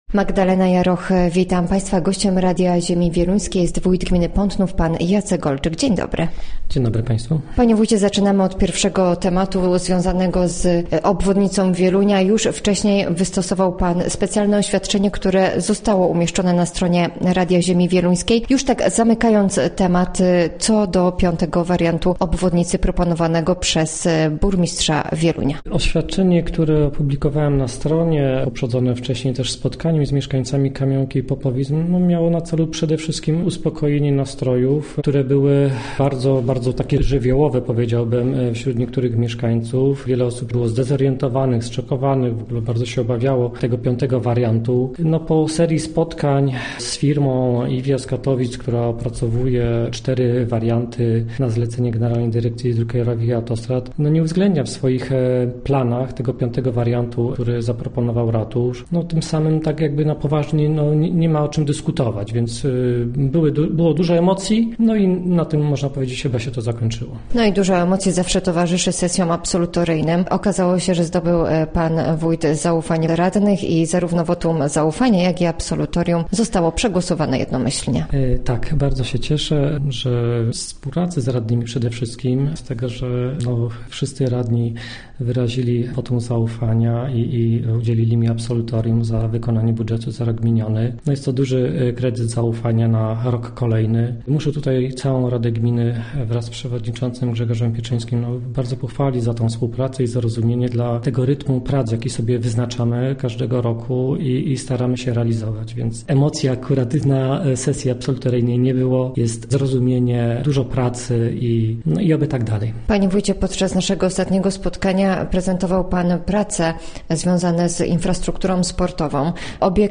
Gościem Radia ZW był Jacek Olczyk, wójt gminy Pątnów